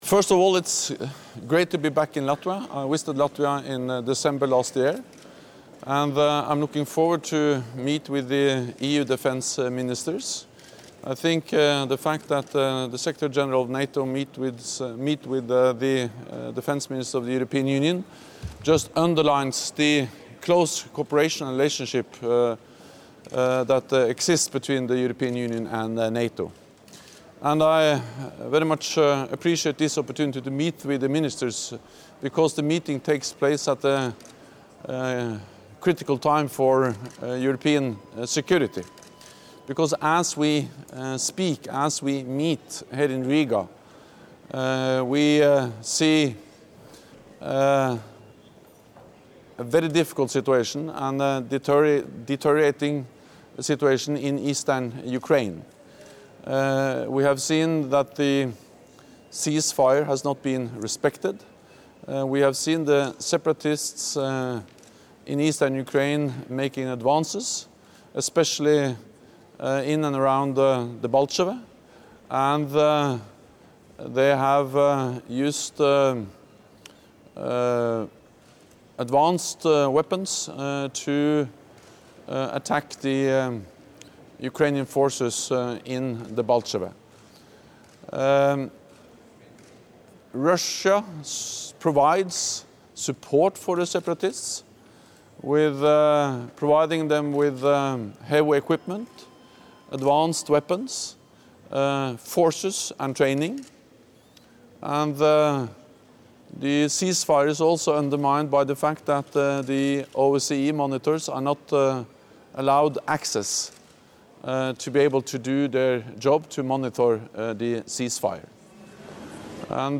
Doorstep statement by NATO Secretary General Jens Stoltenberg prior to the Informal meeting of European Union Defence Ministers in Riga